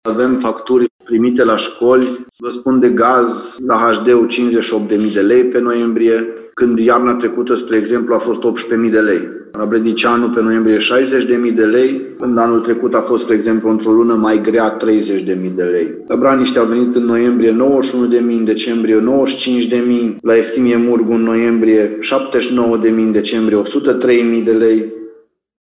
Primarul Lugojului, Claudiu Buciu, spune că se aștepta la majorări, dar sumele datorate i-au depășit toate previziunile.